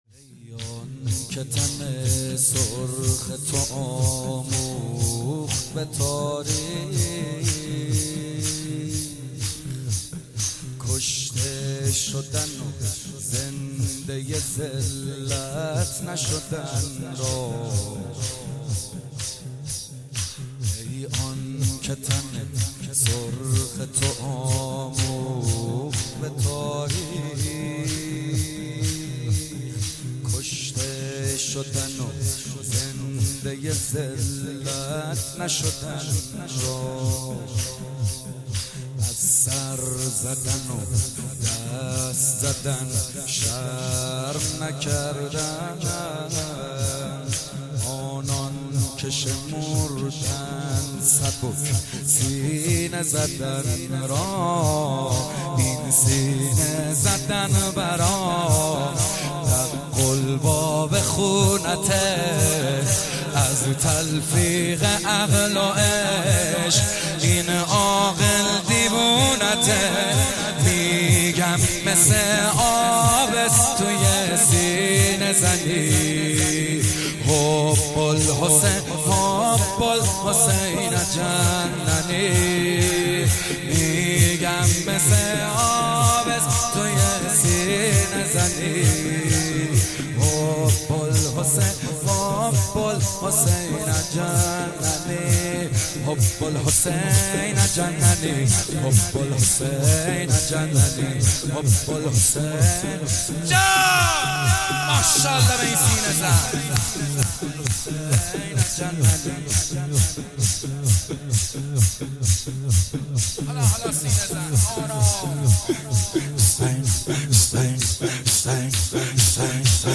مداحی جدید
مراسم هفتگی حسینیه فاطمة الزهرا (س)